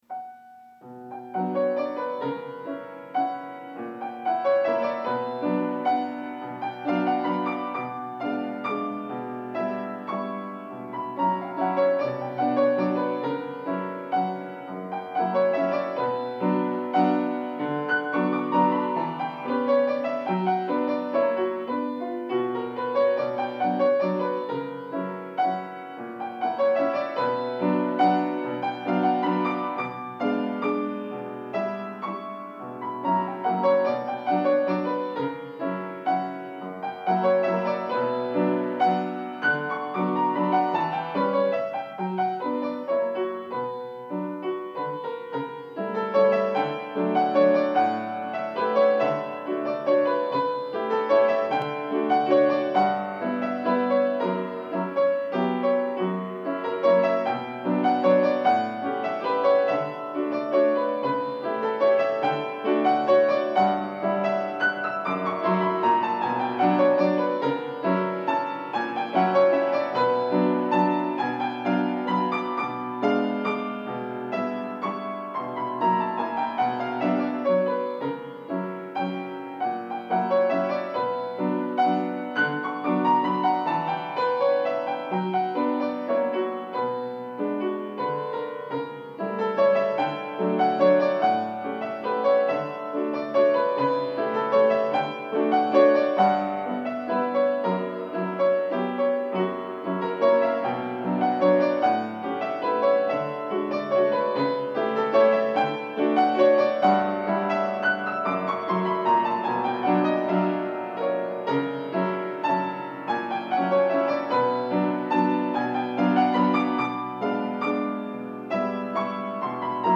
Pas mal de non-jouages, plus pas mal de fautes de frappe.